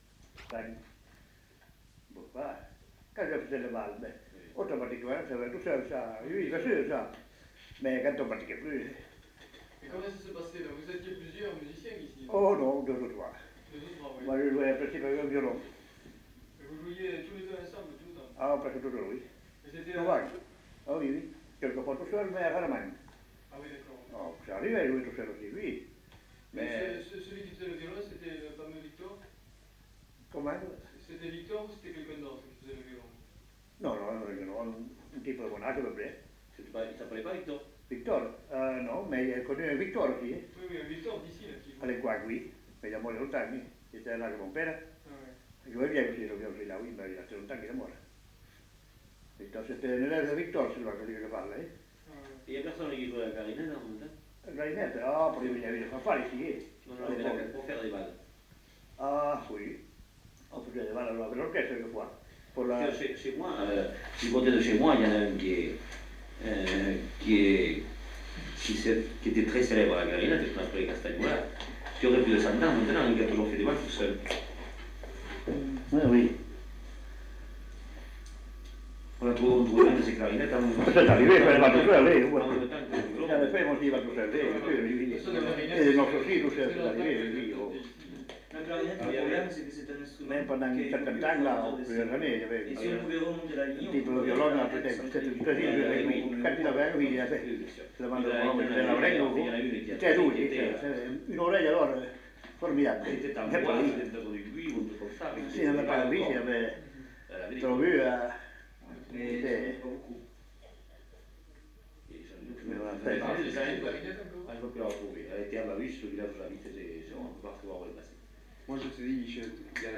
Lieu : Lencouacq
Genre : récit de vie